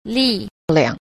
6. 力量 – lìliàng – lực lượng